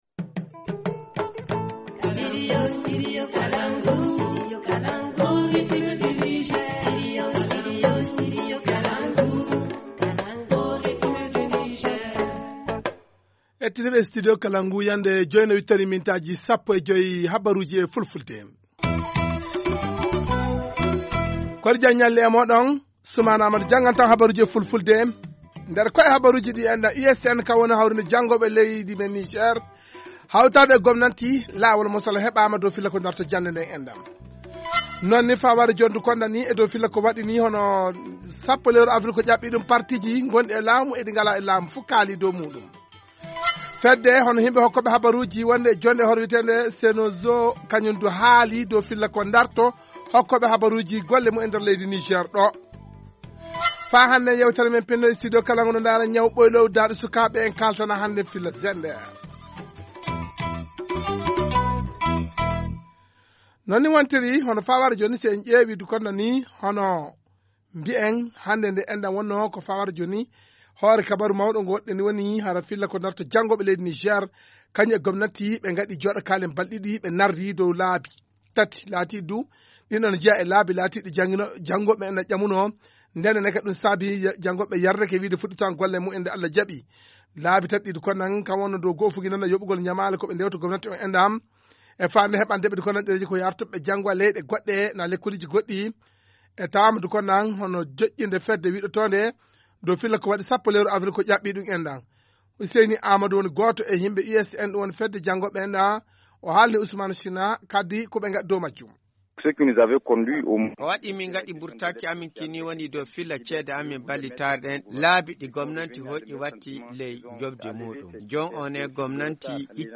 Journal en Francais